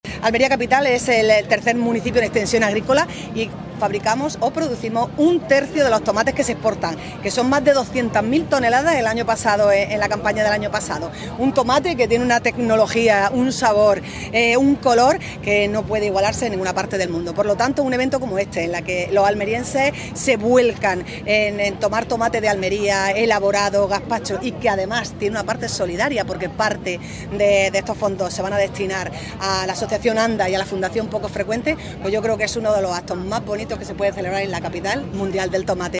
La alcaldesa, el presidente de Diputación y la delegada de la Junta asisten a la XII edición de un multitudinario evento cuya recaudación se destina a ANDA y Fundación Poco Frecuente
ALCALDESA-TOMATE.mp3